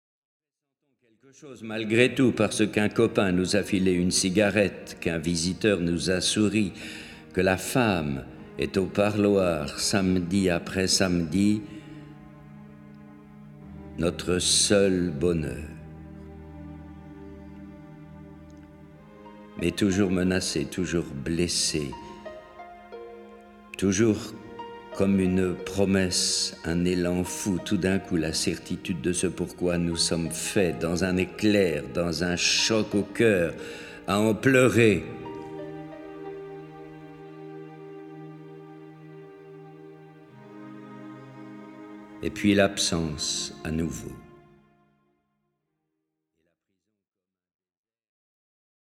Pour donner voix aux sans voix, la revue PRIER et Studio SM ont uni leur savoir-faire pour réaliser cet enregistrement historique de huit prières. Ceux qui ont écrit et lu ces prières, sont tous, plus ou moins, familiersdes médias ; mais ici, il n'est pas question d'interviews ou de reportages...
Format :MP3 256Kbps Stéréo